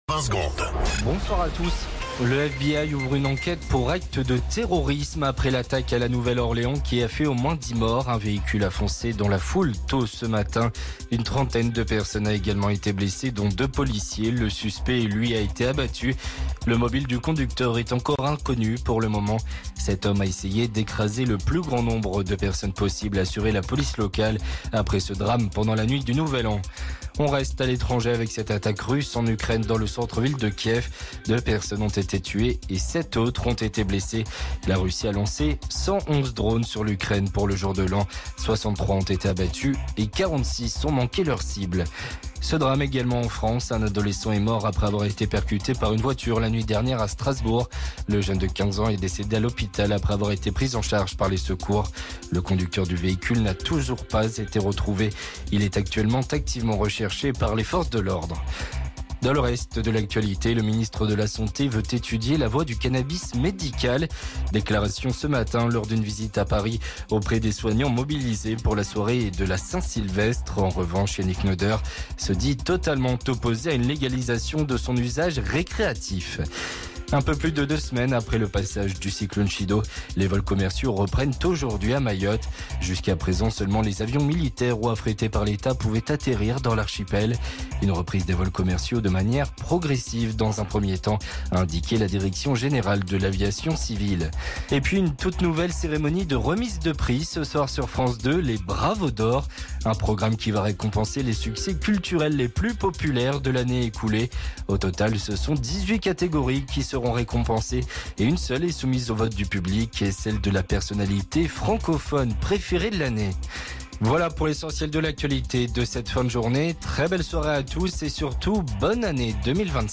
Flash Info National 01 Janvier 2025 Du 01/01/2025 à 17h10 .